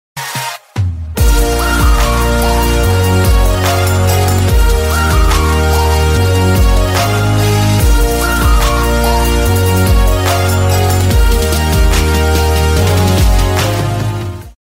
Meme Sound Effect